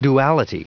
Prononciation du mot duality en anglais (fichier audio)
Prononciation du mot : duality